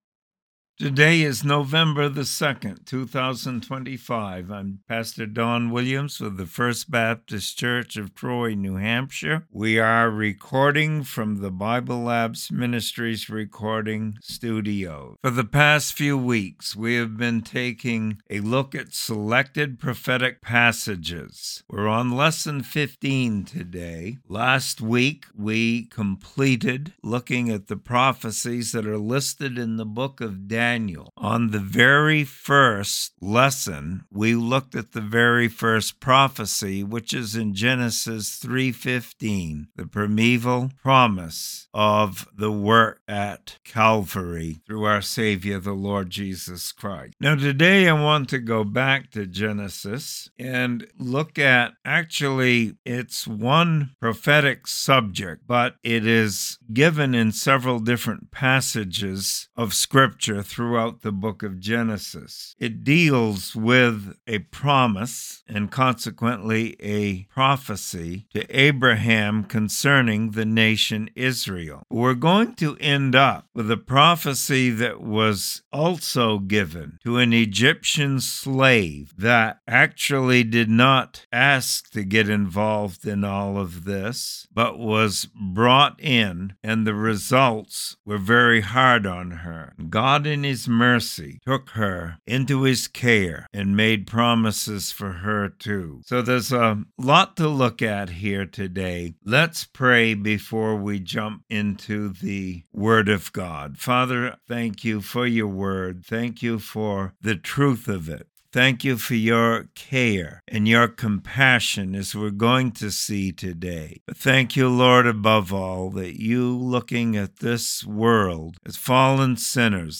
Sermon Library | First Baptist Church of Troy NH